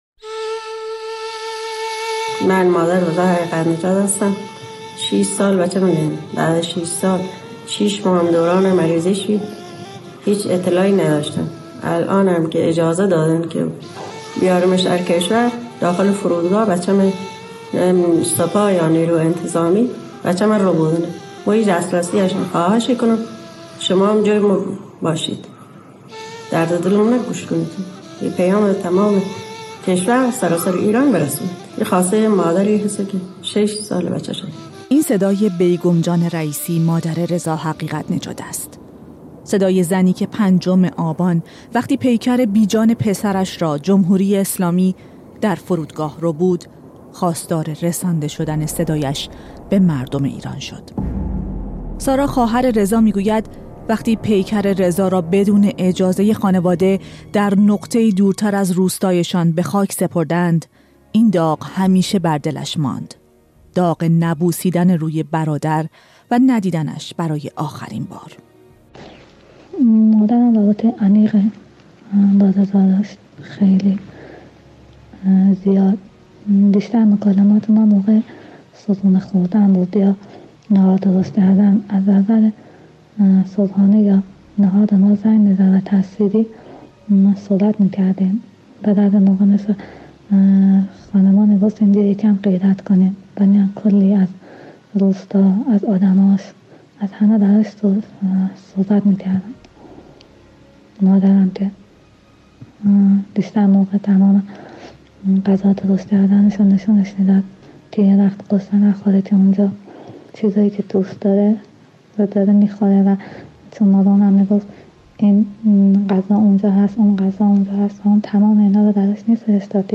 در گفت‌وگو با دوستان، خانواده و همکارانش از او یاد کرده‌ایم.